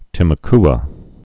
(tĭmə-kə)